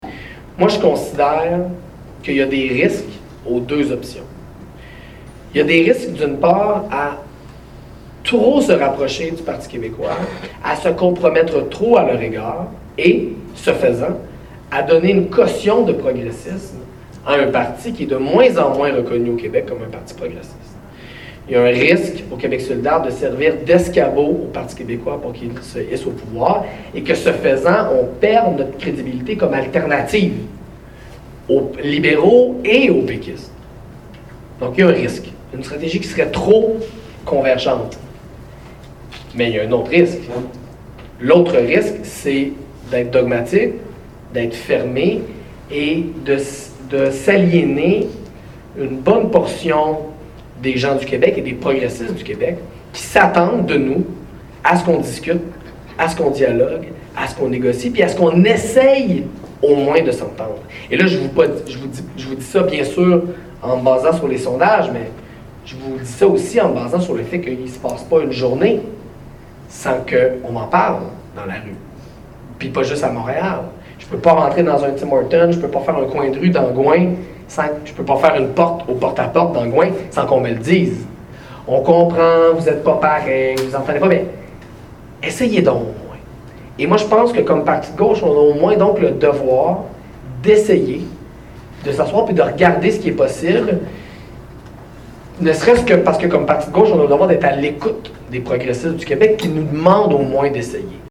en entrevue avec Gabriel Nadeau-Dubois.